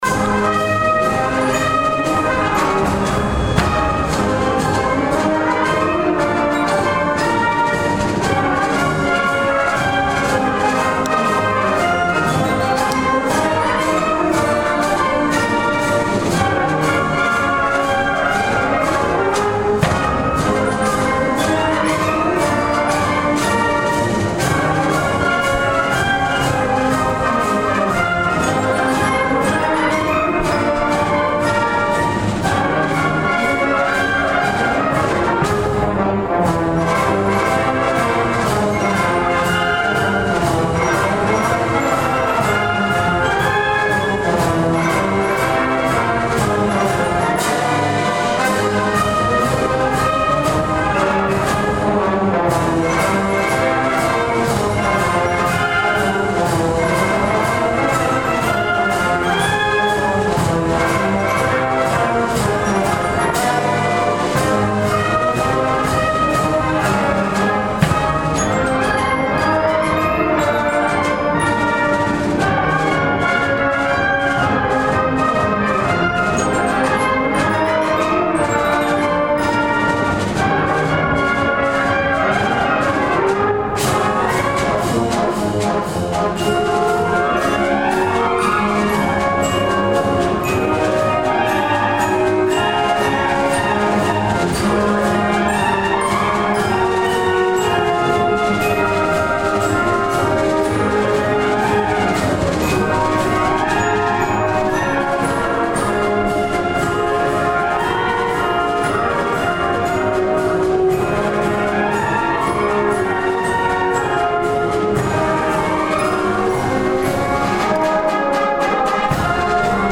The program was held on Sunday afternoon, March 18, at the Decker Auditorium on the campus of Iowa Central Community College.
Karl L. King's march "Bon Voyage" was performed when the Irish Concert was nearly concluded.